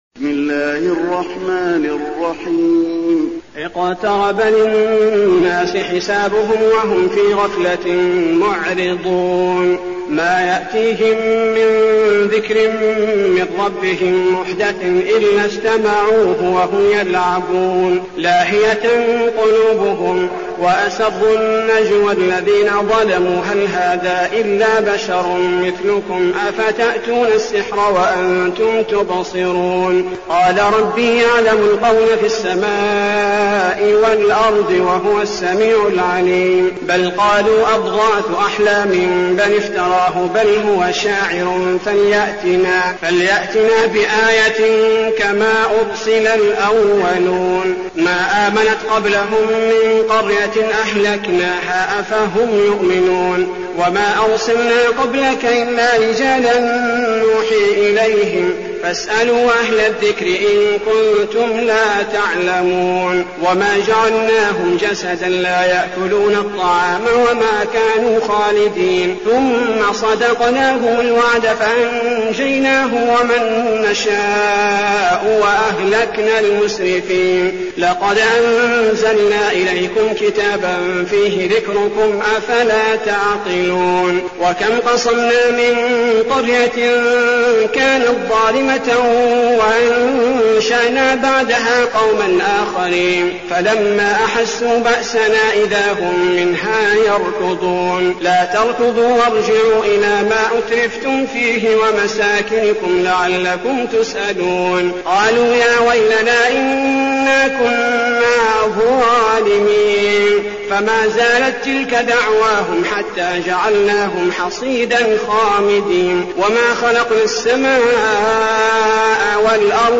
المكان: المسجد النبوي الأنبياء